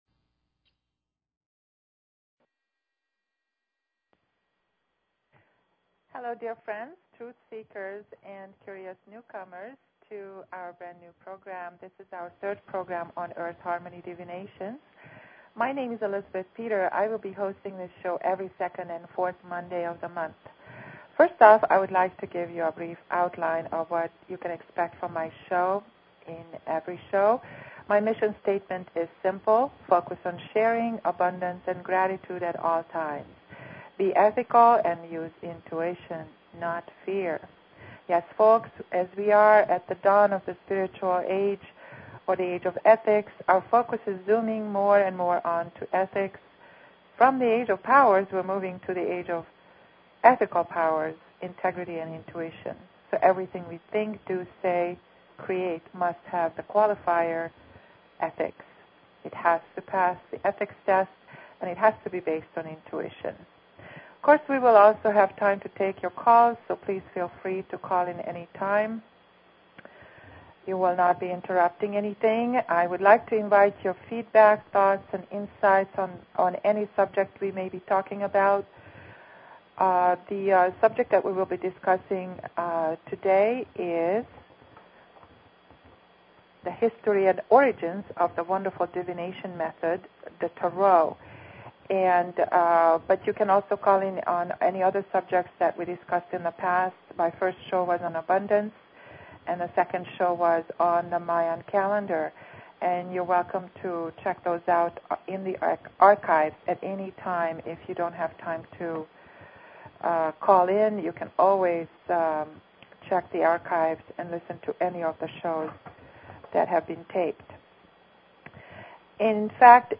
Detailed insight into the Egyptian origins and symbolism of the most ancient divination tool: the TAROT. Earth Harmony Divinations Talk Show